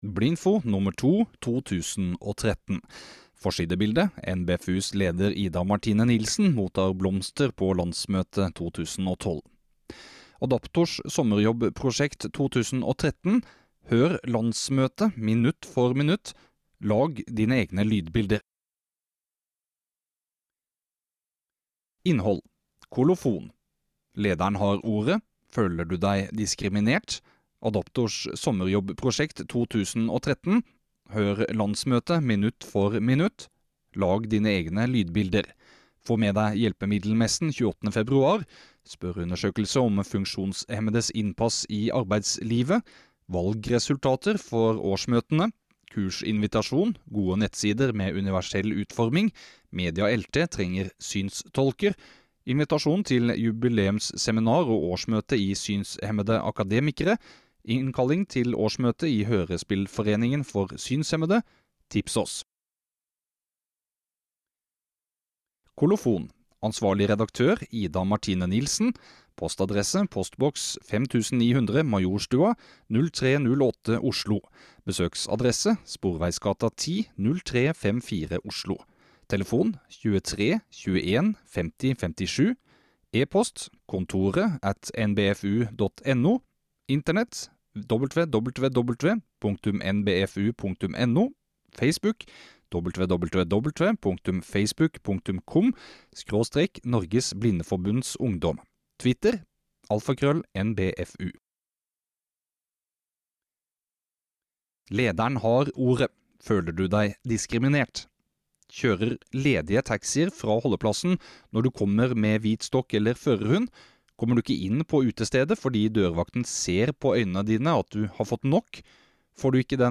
Adaptors sommerjobbprosjekt, landsmøteradio, kurs i lydopptak, kursinvitasjoner og mer. Les tekstutgaven eller hør den innleste som mp3-fil.